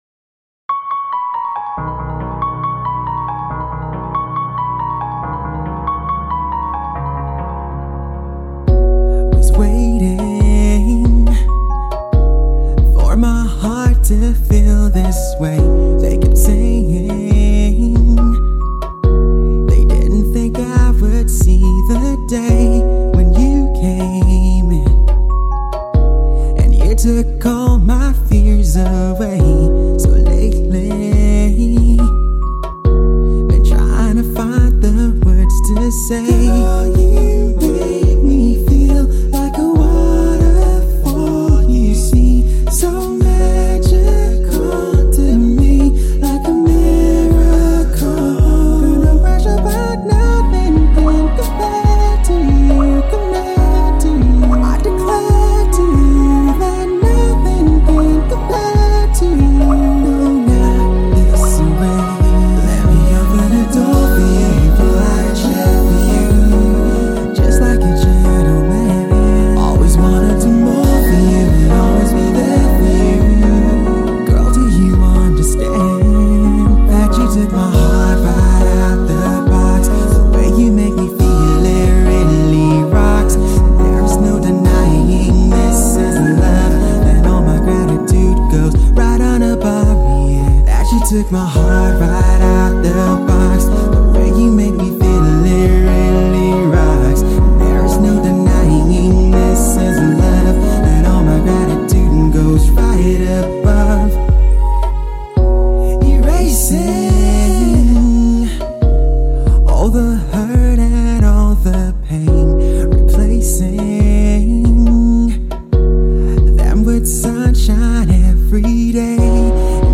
soft-voiced singer
is more R&B and is somewhat like a touching love letter